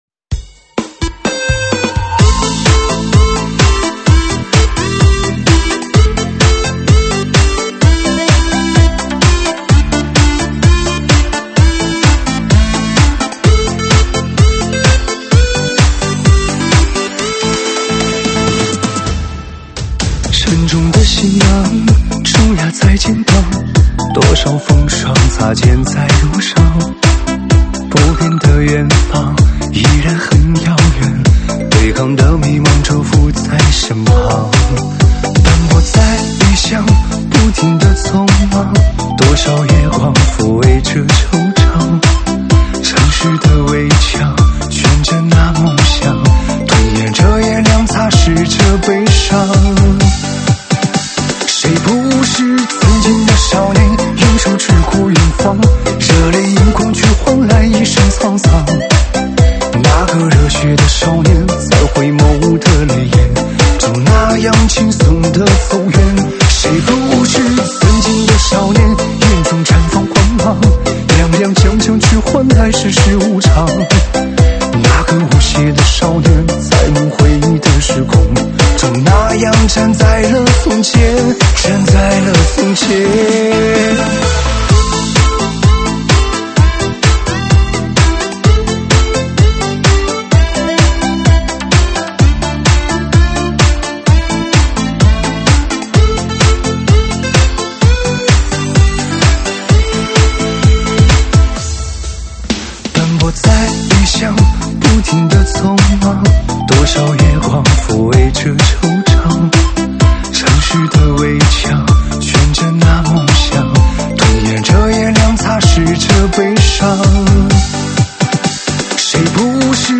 舞曲编号：76175
舞曲类别：周榜单